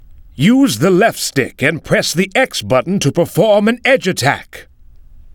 From The Cutting Room Floor Jump to navigation Jump to search File File history File usage Metadata All03_h27_so.ogg  (Ogg Vorbis sound file, length 5.4 s, 252 kbps) This file is an audio rip from a(n) Xbox 360 game. This file is an audio rip from a(n) PlayStation 3 game.